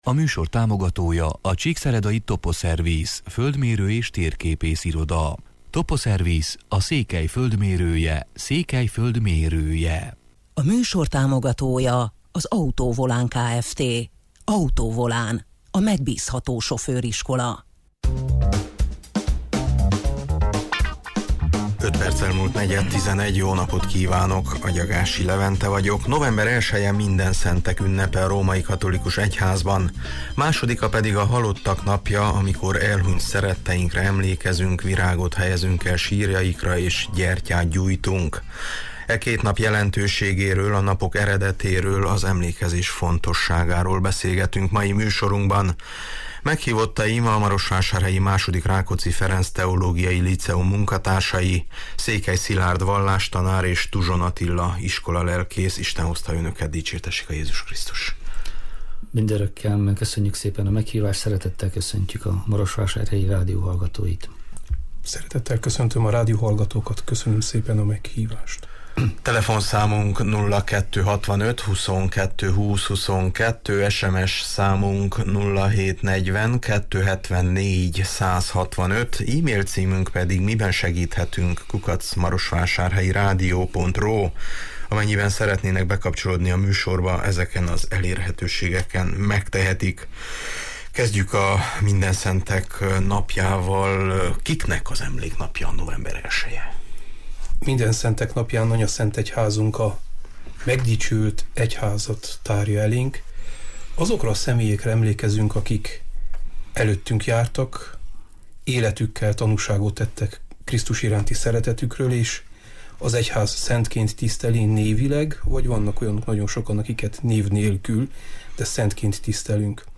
November elseje Mindenszentek ünnepe a római katolikus egyházban, másodika pedig a Halottak napja, amikoris elhunyt szeretetteinkre emlékezünk, virágot helyezünk el sírjaikra és gyertyát gyújtunk. E két nap jelentőségéről, a napok eredetéről, az emlékezés fontossáágáról beszélgetünk mai műsorunkban.